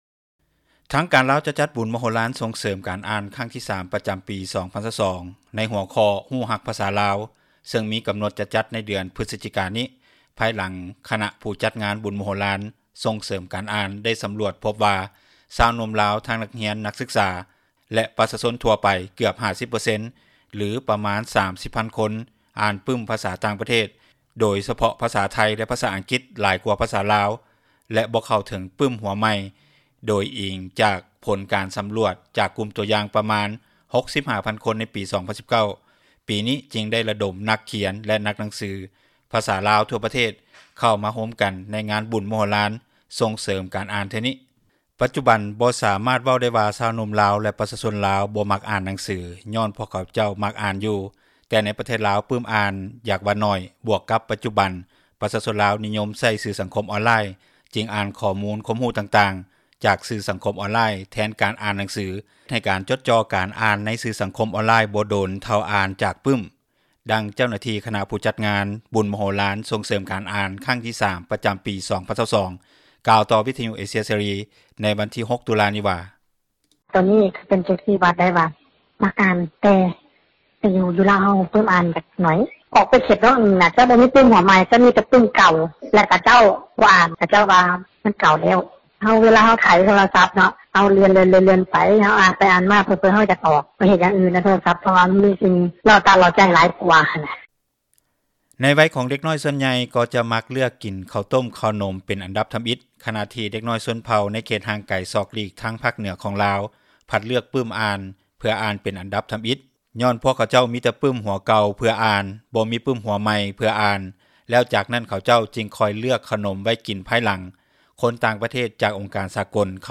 ດັ່ງເຈົ້າໜ້າທີ່ ຄະນະຜູ້ຈັດງານບຸນ ມະໂຫລານສົ່ງເສີມ ການອ່ານຄັ້ງທີ 3 ປະຈຳປີ 2022 ກ່າວຕໍ່ວິທຍຸ ເອເຊັຽເສຣີ ໃນວັນທີ 06 ຕຸລາ ນີ້ວ່າ: